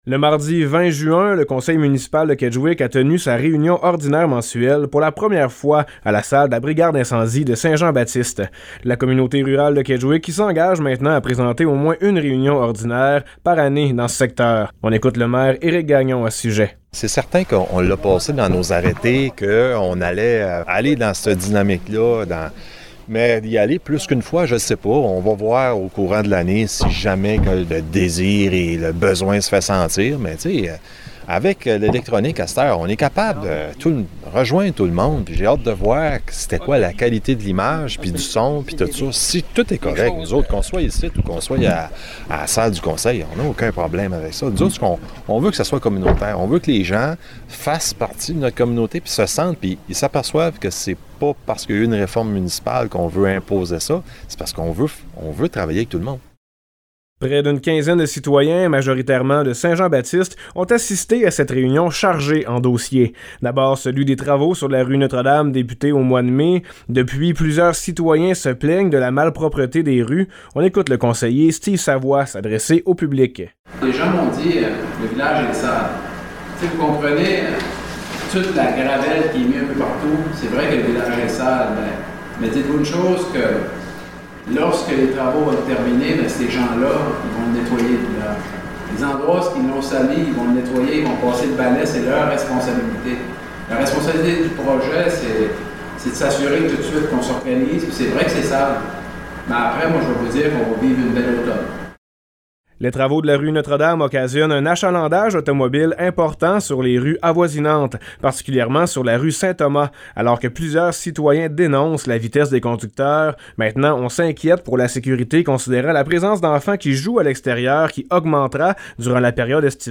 Le conseil municipal de la communauté rurale de Kedgwick a tenu, pour une première occasion, leur réunion ordinaire mensuelle dans la salle de la brigade d’incendie de Saint-Jean-Baptiste-de-Restigouche.
Dans le reportage suivant, plusieurs points sont abordés par le maire, les conseillers et les citoyens. Notamment, on revient sur les travaux de la rue Notre-Dame ainsi que sur les entrées par effractions qui mènent la vie dure aux policiers.